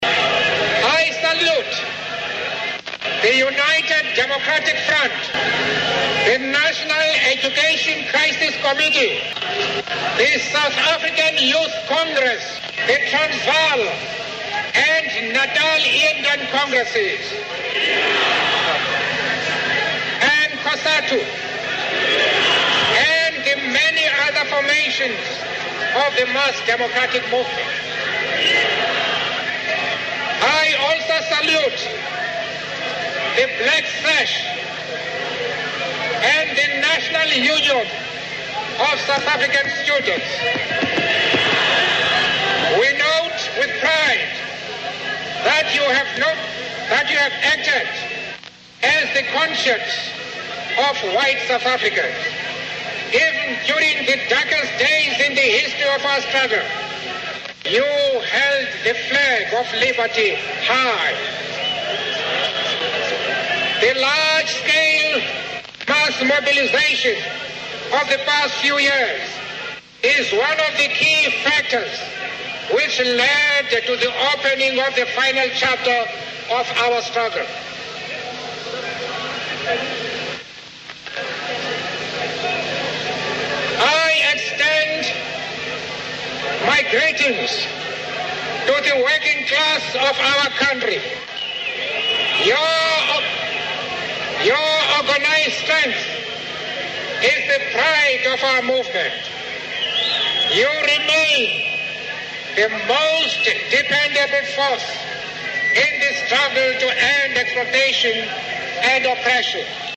名人励志英语演讲 第30期:为理想我愿献出生命(4) 听力文件下载—在线英语听力室